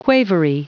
Prononciation du mot quavery en anglais (fichier audio)
Prononciation du mot : quavery